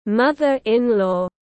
Mẹ vợ (mẹ chồng) tiếng anh gọi là mother-in-law, phiên âm tiếng anh đọc là /ˈmʌð.ə.rɪn.lɔː/.
Mother-in-law /ˈmʌð.ə.rɪn.lɔː/